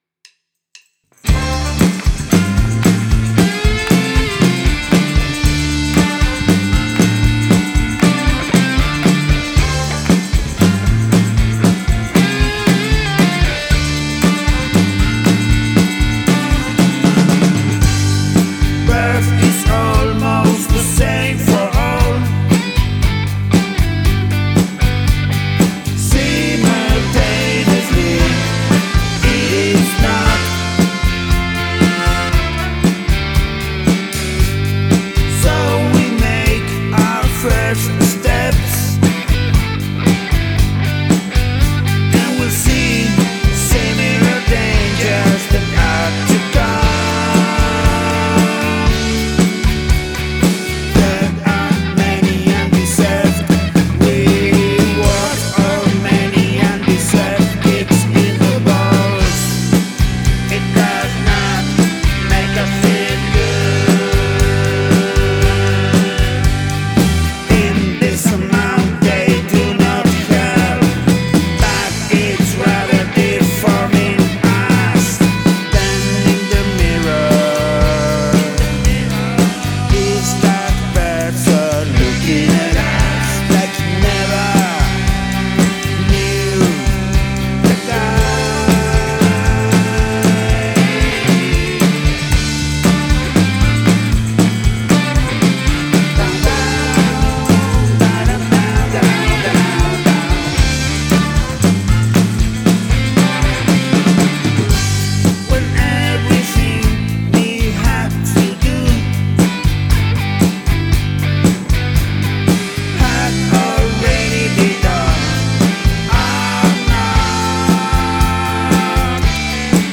Love the lead guitar sound.
The horns blend very well with the rest of the song.